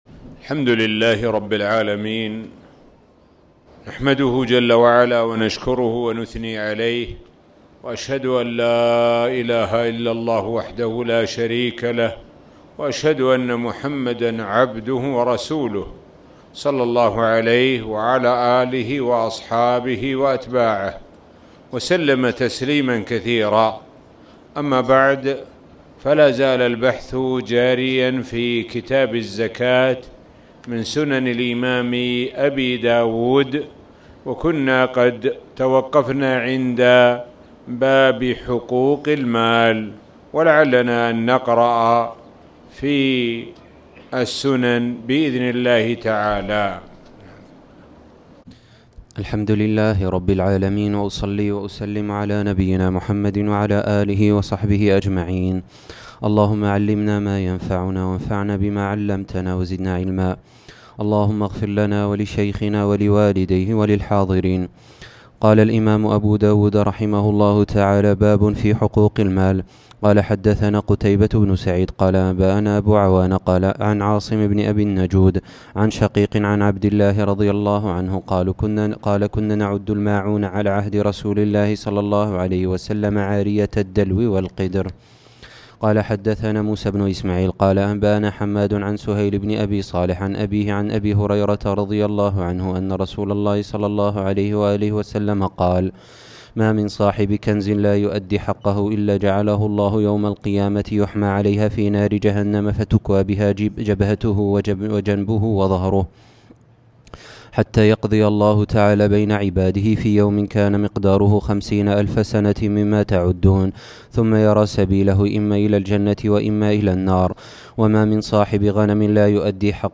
تاريخ النشر ١٧ رمضان ١٤٣٨ هـ المكان: المسجد الحرام الشيخ: معالي الشيخ د. سعد بن ناصر الشثري معالي الشيخ د. سعد بن ناصر الشثري باب في حقوق المال